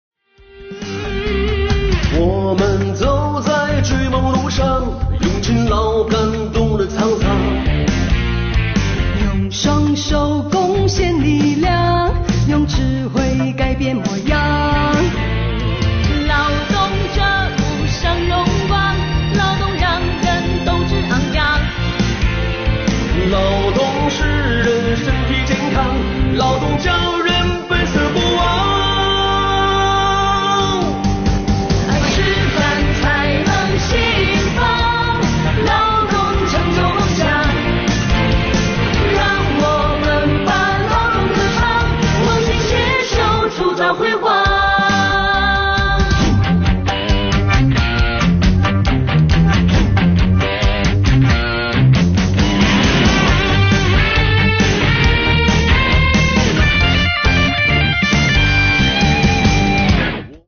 劳动接力唱有奖征集活动
主题鲜明，旋律激扬澎湃
本次传唱的歌曲是由恩山作词、李凯稠作曲的《让我们把劳动歌唱》。这首歌节奏欢快，旋律动感，唱出了劳动者的心声，唱出了新时代的风貌。歌词句句铿锵有力、振奋人心。